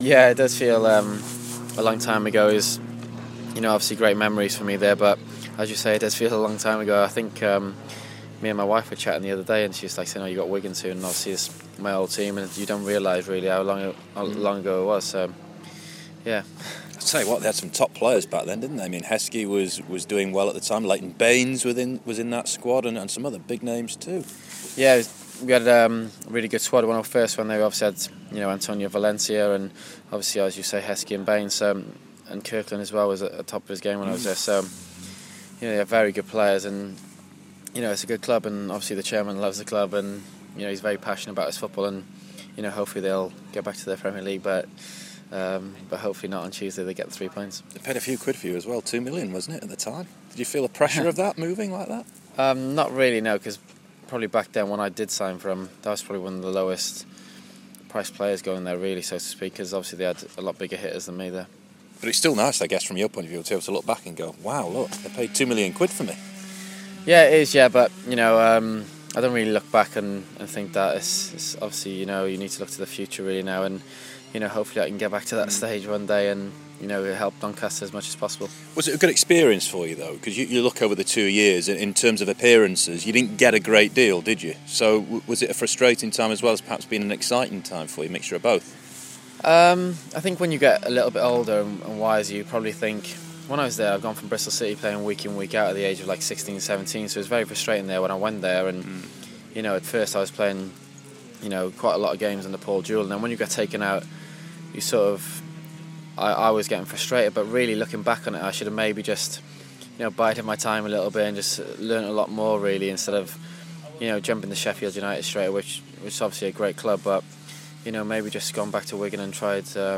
David Cotteril speaks to BBC Radio Sheffield